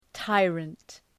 {‘taırənt}
tyrant.mp3